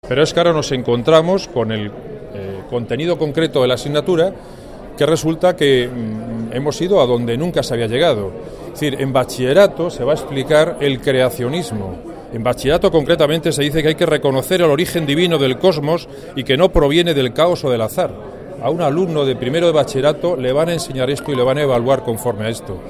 Declaraciones de Mario Bedera sobre la Religión en los colegios y los institutos 26/2/2015